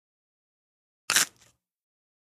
EatCrispHardCrunch PE678006
DINING - KITCHENS & EATING CRISPY SNACK: INT: Single crunch, detailed.